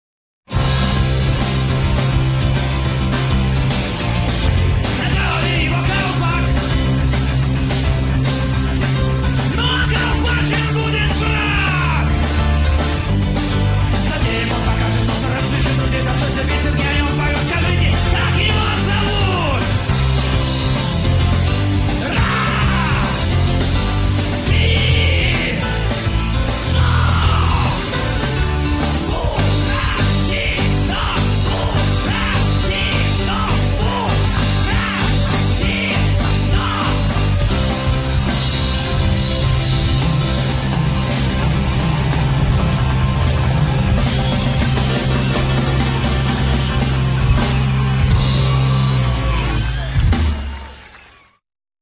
Шаболовка(1995)
фрагмент песни ( 52 сек.)